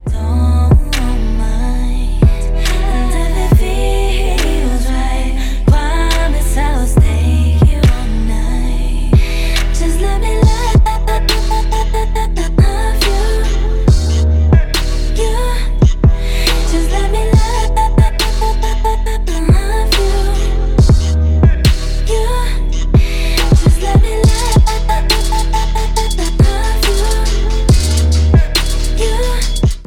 • Dance